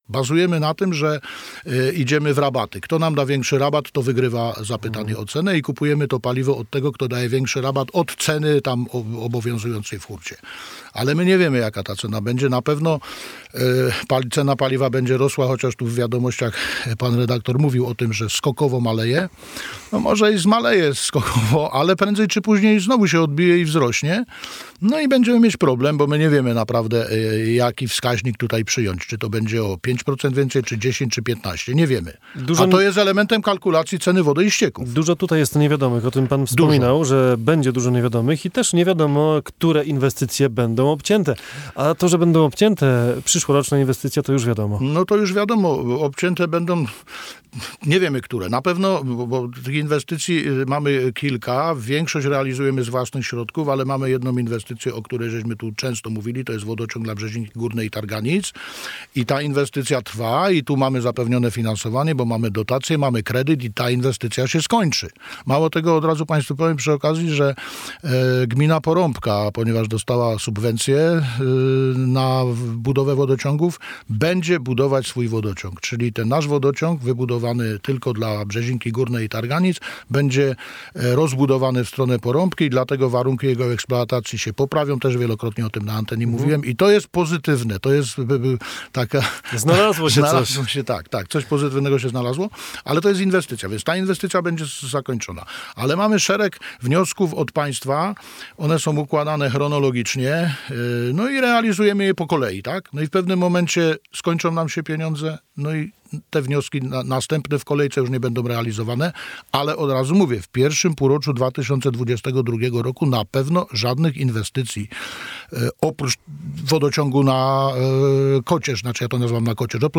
Audycja w Radio Andrychów z dnia 8.09.2021